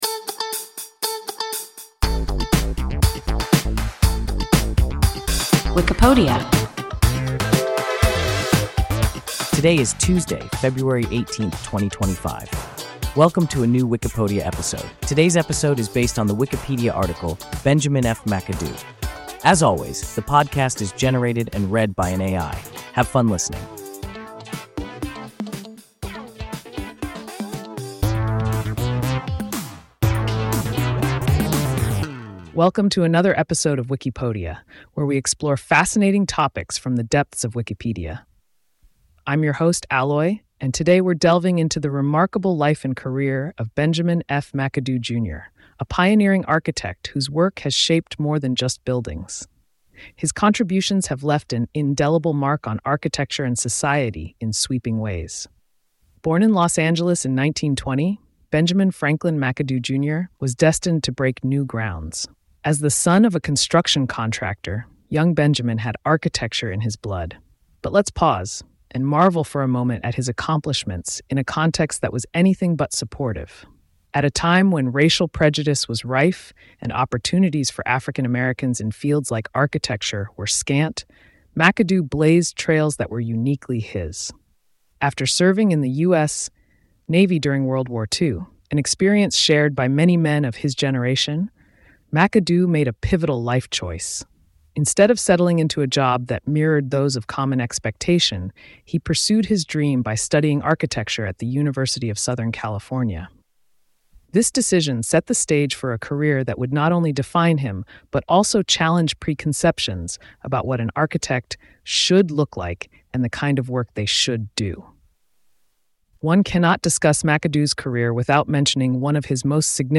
Benjamin F. McAdoo – WIKIPODIA – ein KI Podcast